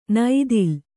♪ naidil